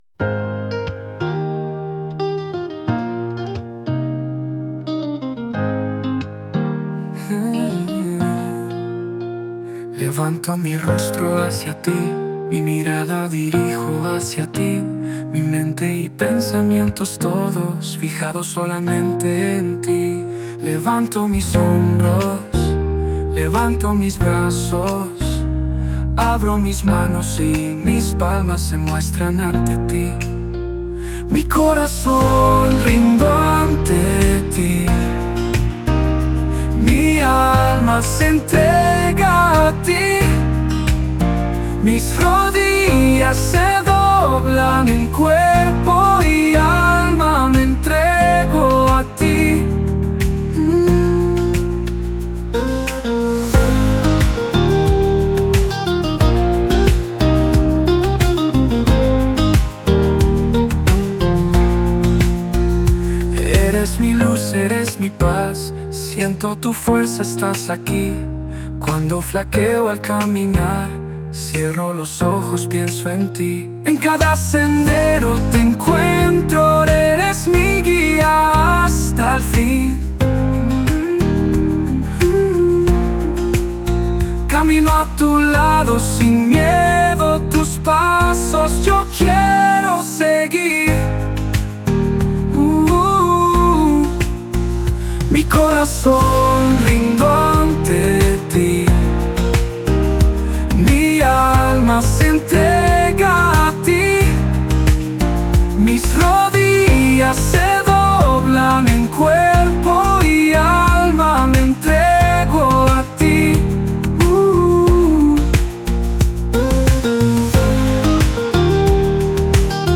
R and B